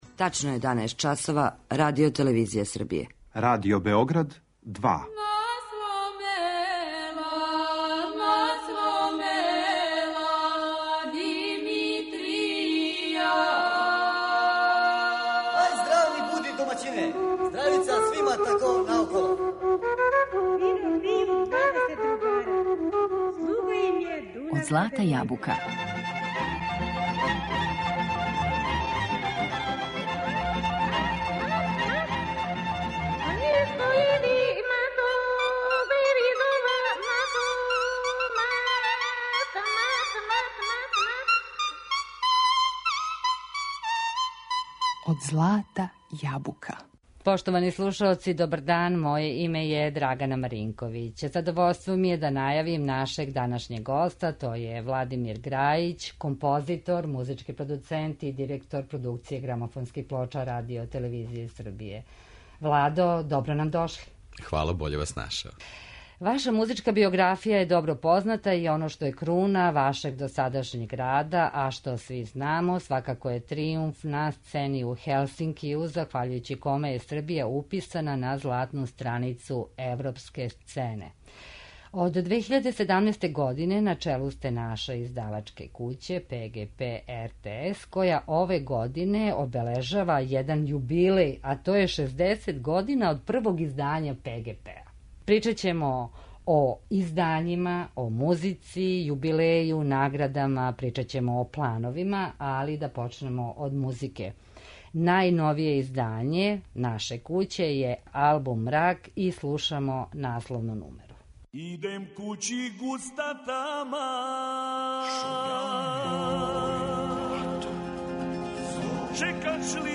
Музички гост у емисији Од злата јабука биће Владимир Граић, композитор, музички продуцент и директор ПГП РТС-а